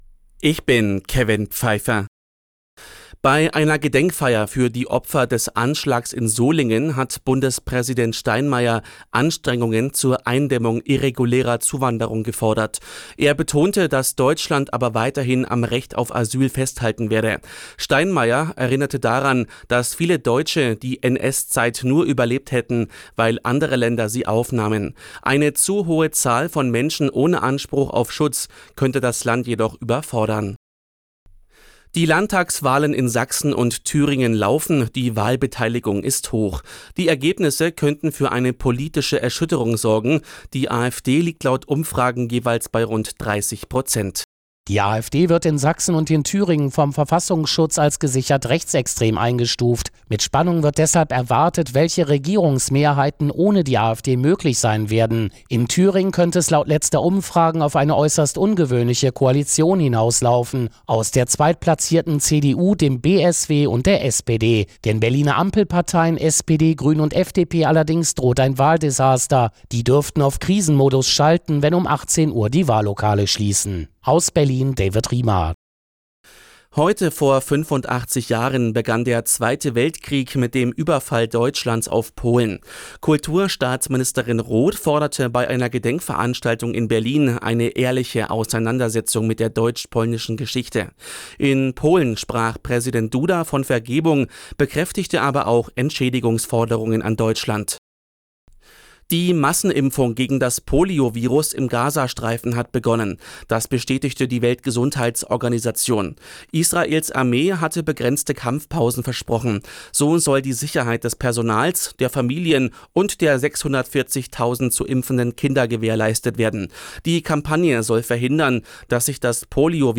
Die Arabella Nachrichten vom Sonntag, 1.09.2024 um 15:59 Uhr - 01.09.2024